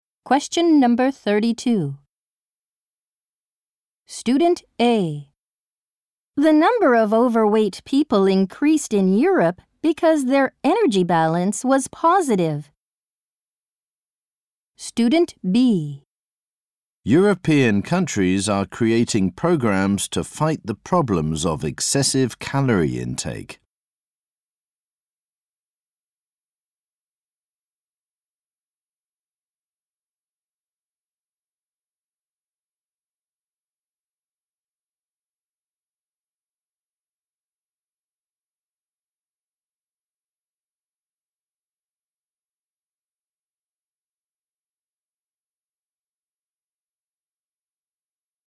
○共通テストの出題音声の大半を占める米英の話者の発話に慣れることを第一と考え，音声はアメリカ（北米）英語とイギリス英語で収録。
[サンプル音声]
【第7回】（新）第5問　問27～31（アメリカ（北米）英語）